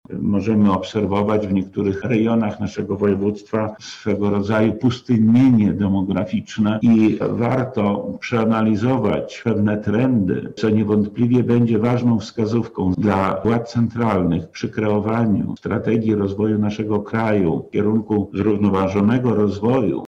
O tym dyskutowali eksperci na konferencji naukowej poświęconej demografii.
W skali kraju województwo lubelskie ma najniższe dochody PKB na jednego mieszkańca – wskazuje wojewoda lubelski Lech Sprawka.